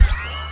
CBHQ_CFO_magnet_on.mp3